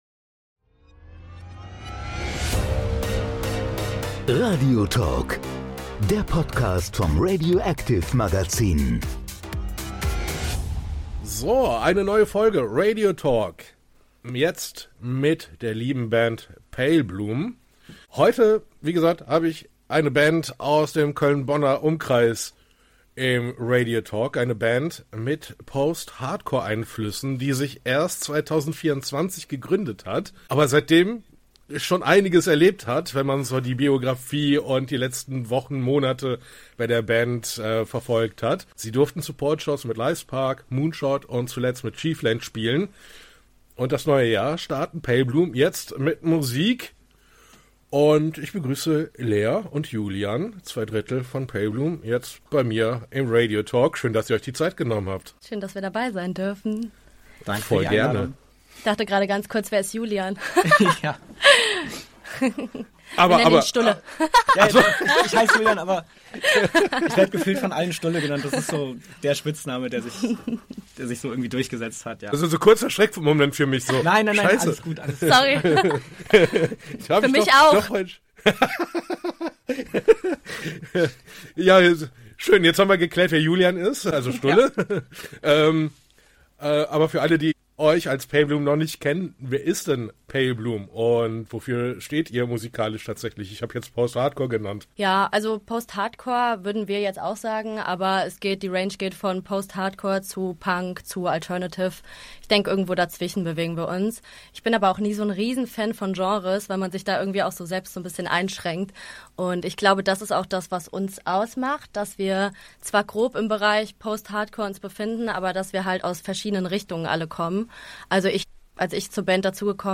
Hier bekommst du spannende Interviews und Gespräche aus der Musikwelt.